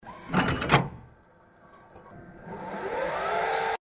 money_in_start.mp3